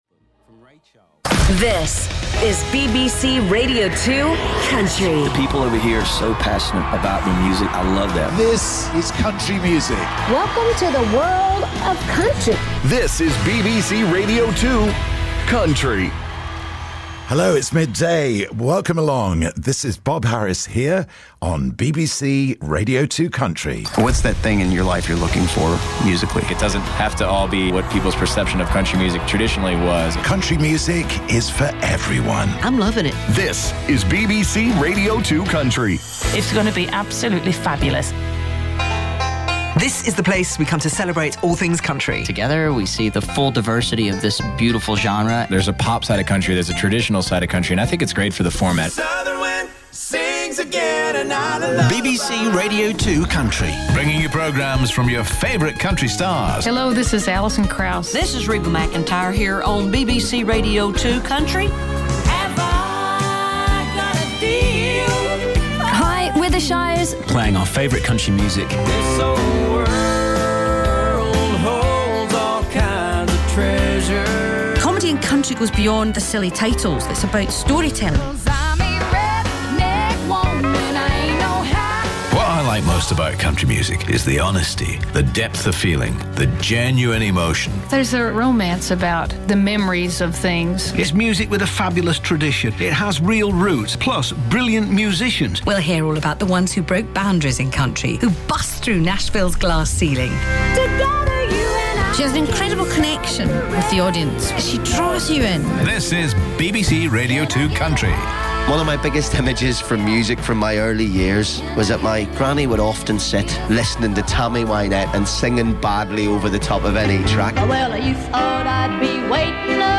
The first few minutes of production to launch the station on March 9th 2017